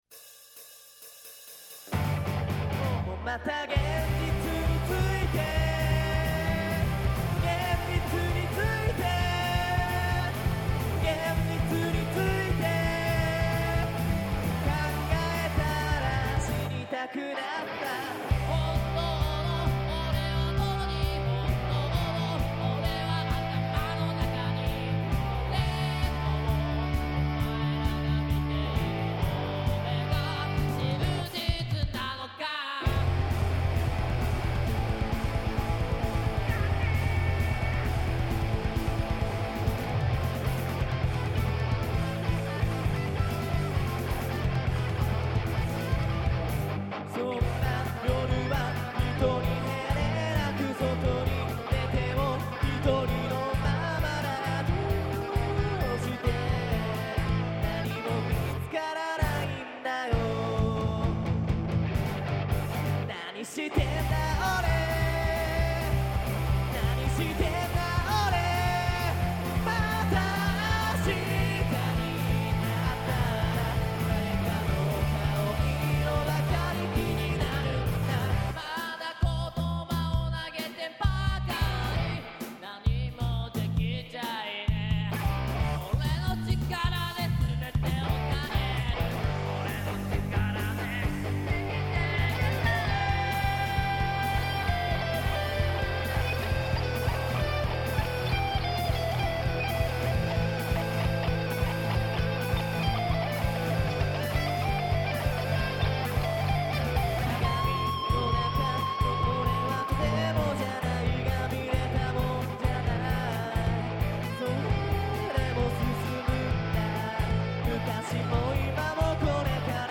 サーカスから抜け出して町で暴れる珍獣(?)のような音楽性…ぜひライブで一度みてもらいたいです。